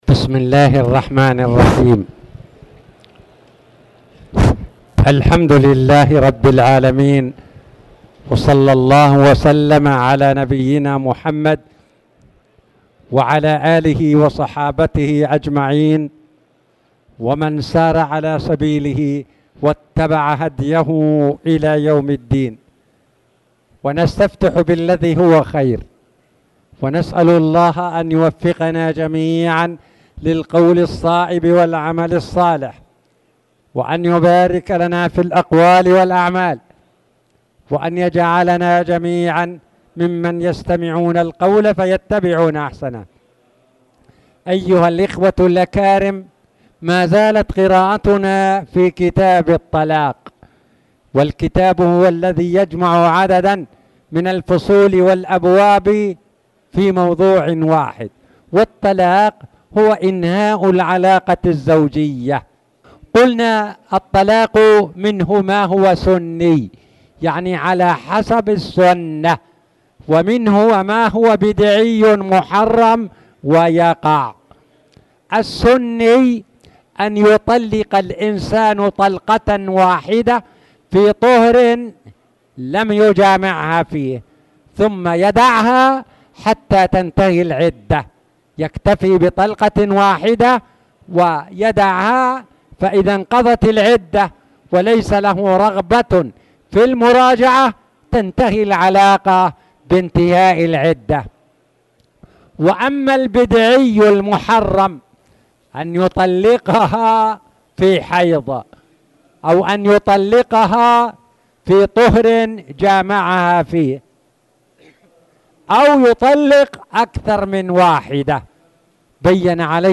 تاريخ النشر ١٦ جمادى الآخرة ١٤٣٨ هـ المكان: المسجد الحرام الشيخ